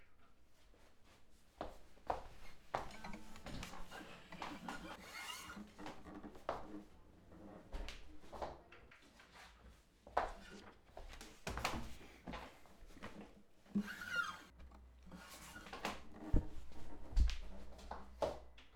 2015 Leerlaufcuts (Studio-Aufnahmen Leerstellen)